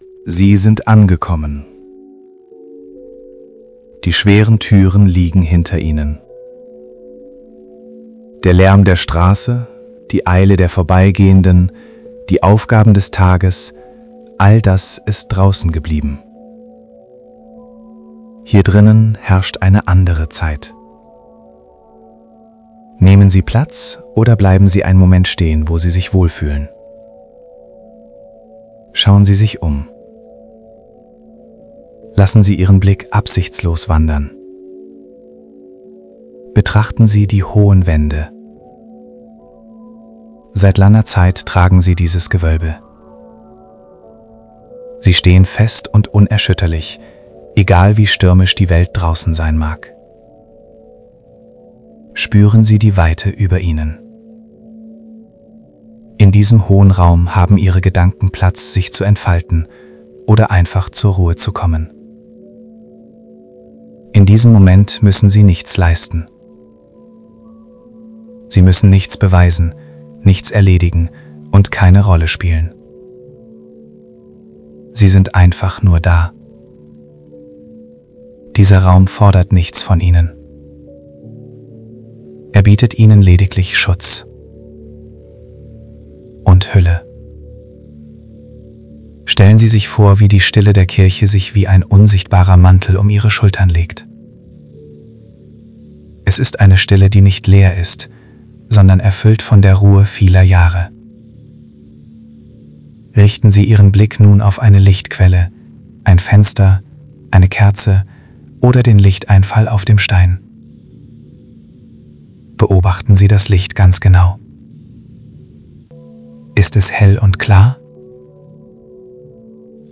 St-Paul_Kirchenmeditation_mp3